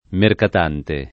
merkat#nte] s. m. — ant. o lett. per «mercante» — anche mercadante [merkad#nte], già preval. in usi region. (spec. settentr.), nel sign. proprio di «mercante» (e senza un preciso collegam. con mercatare) — sim. i cogn. Mercadante, ‑ti, Mercatante, ‑ti